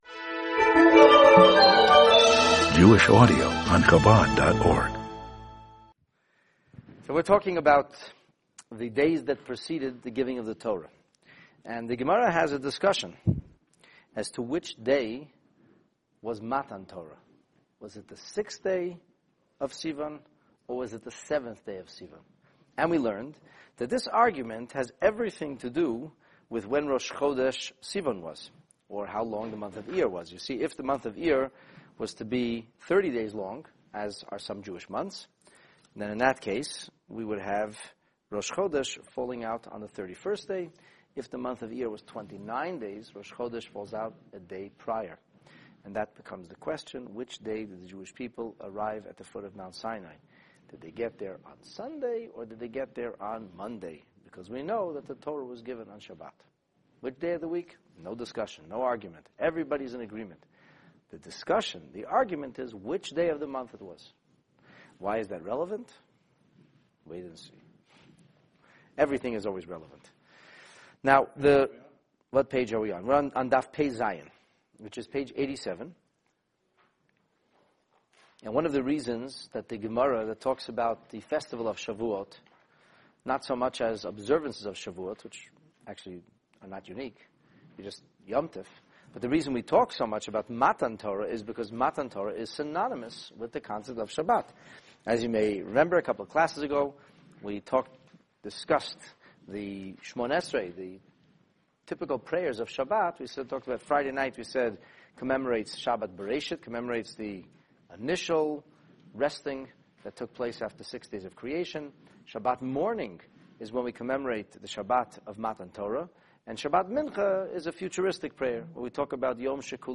Moses Embracing Celibacy and Breaking the Tablets : The Talmud on preparing for the giving of the Torah (Part 2) In this Talmud class we analyze the rest of the actions Moshe Rabbeinu appears to have unilaterally ordained, in addition to adding an extra day of preparation for Revelation at Sinai. See just how Moses, the unparalleled master prophet deduced the will of G-d and (although uninstructed) chose to embrace a life of celibacy from Matan Torah onward, and elected to shatter the Luchot upon seeing the Jewish people’s spiritual debauchment in worshipping the hastily fashioned Golden Calf!